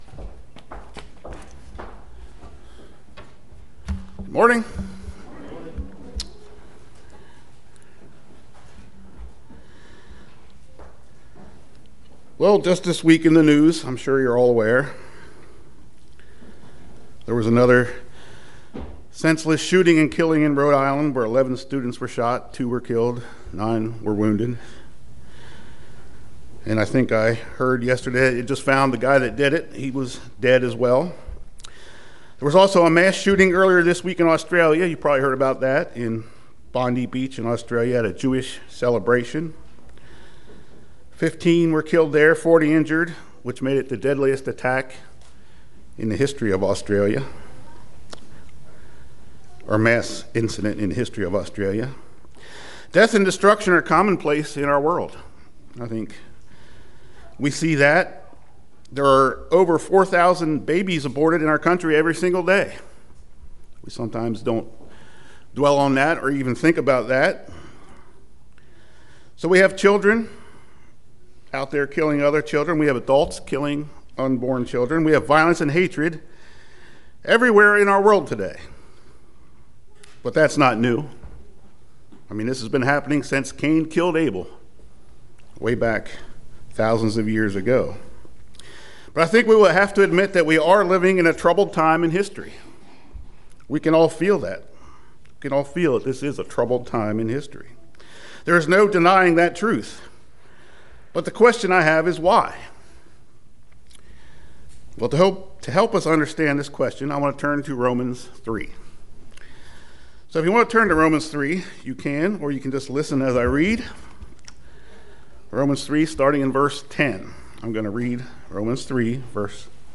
Hypocrisy can sneak into our lives when we lose awe of our heavenly Father. This sermon dives into the importance of why and how we must go about instilling this Godly fear into our hearts and minds in order to prevent hypocrisy.
Given in Lewistown, PA